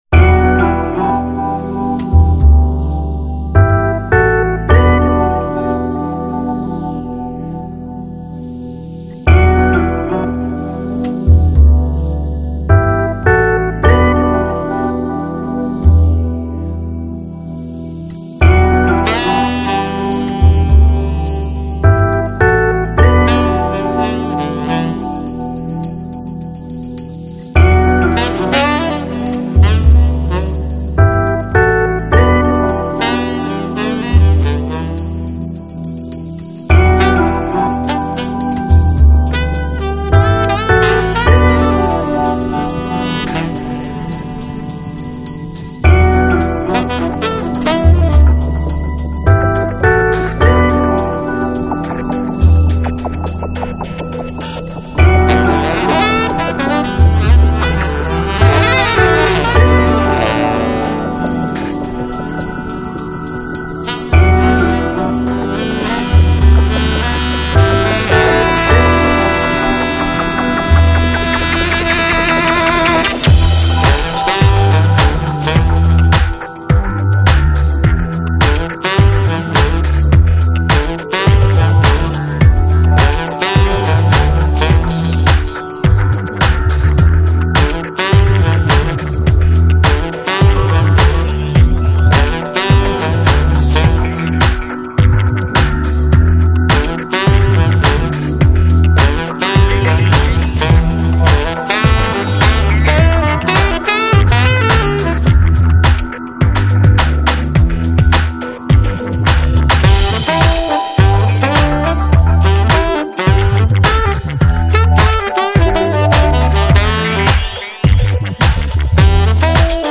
Жанр: Electronic, Lounge, Chill Out, Downtempo, Balearic